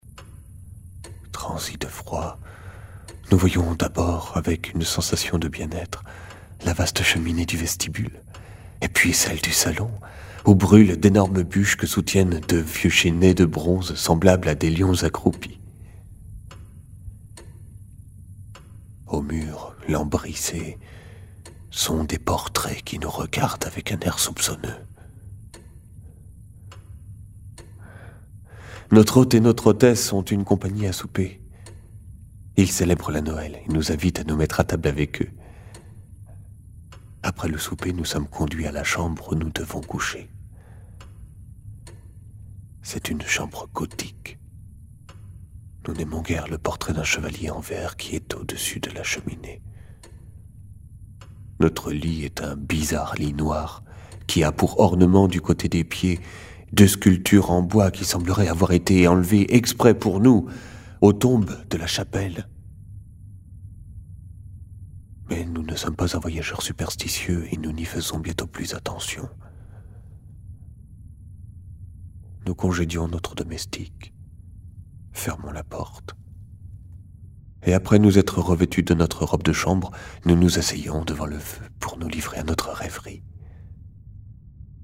LE CHEVALIER VERT - Conte sonorisé et joué Durée : 10mn 25s Un voyageur est hébergé pour la nuit dans un château.
HISTOIRES DE FANTÔMES – lecture performée